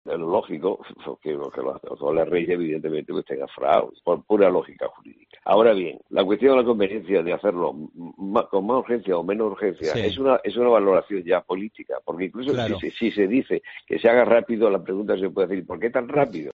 AUDIO: Entrevista a Ángel Juanes, Pdte. del Tribunal Supremo